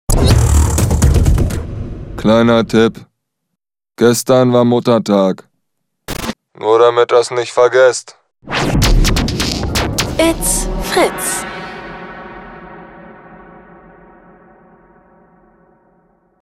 Gestern war Muttertag | Fritz Sound Meme Jingle